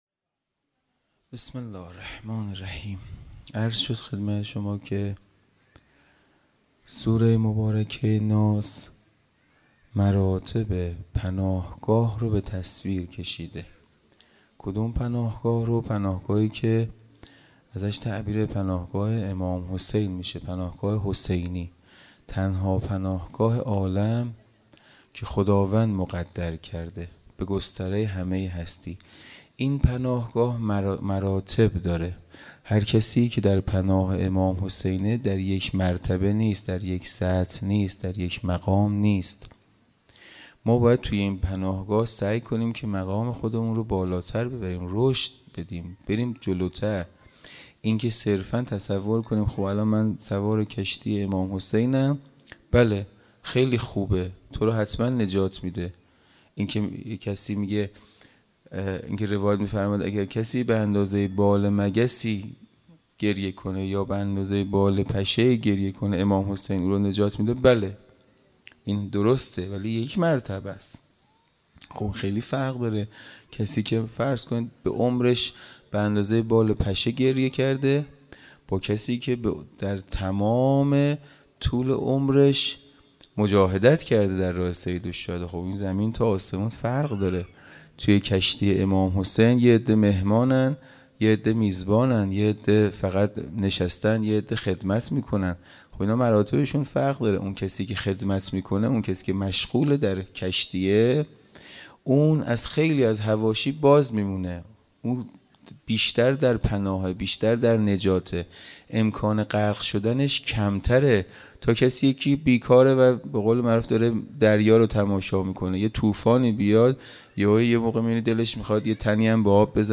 خیمه گاه - حسینیه کربلا - شام غریبان-سخنرانی
حسینیه کربلا